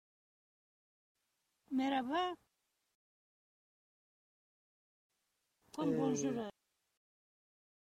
uitspraak uitleg Merhaba?